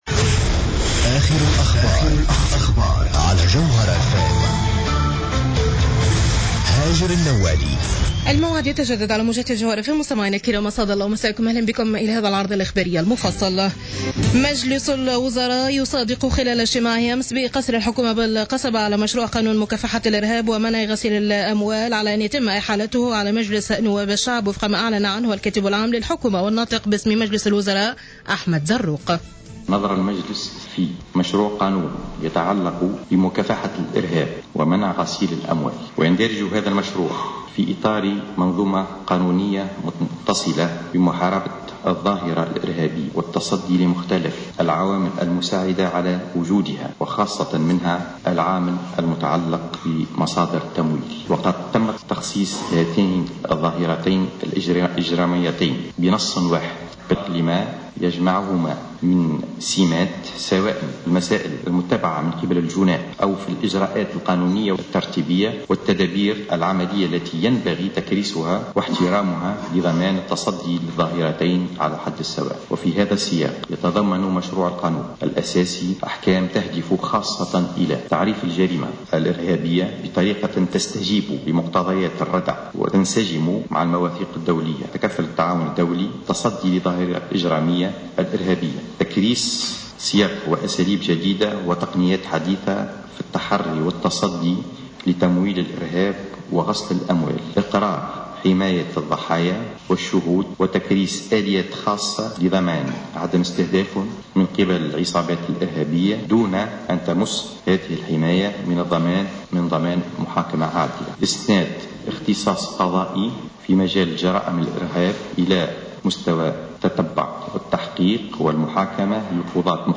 نشرة أخبار منتصف الليل ليوم الخميس 26 مارس 2015